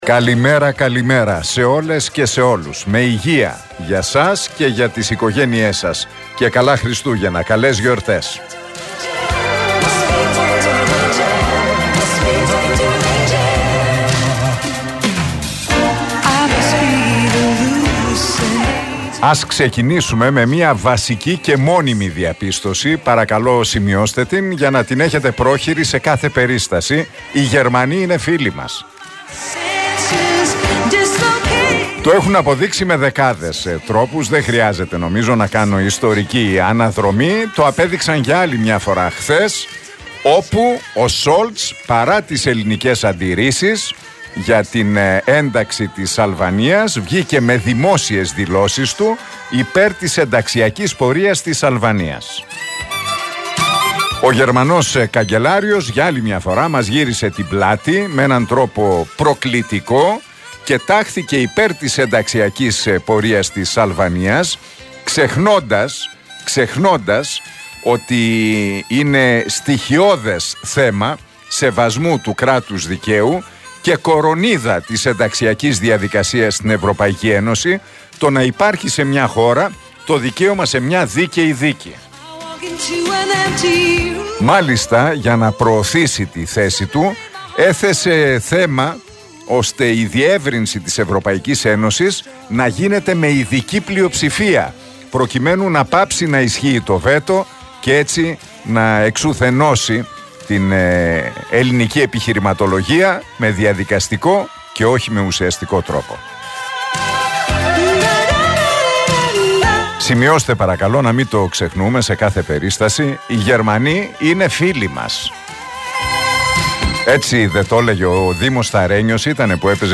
Ακούστε το σχόλιο του Νίκου Χατζηνικολάου στον RealFm 97,8, την Πέμπτη 14 Δεκεμβρίου 2023.